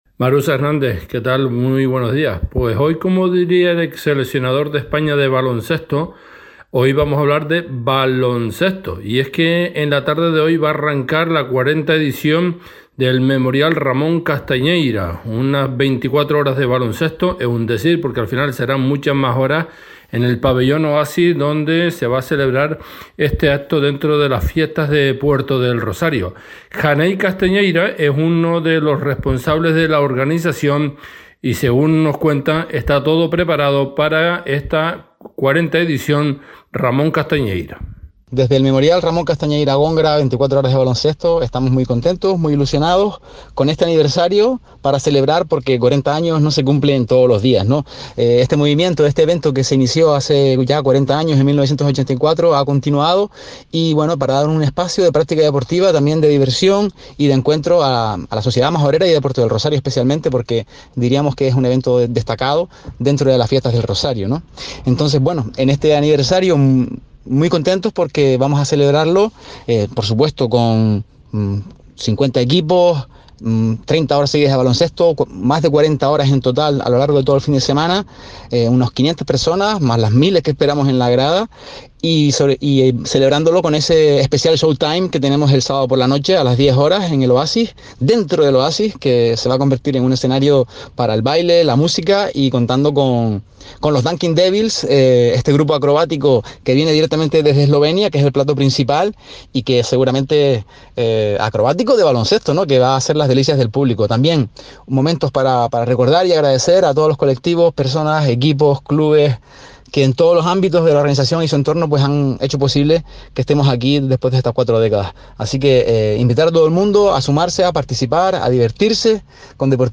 A Primera Hora, crónica deportiva
Entrevistas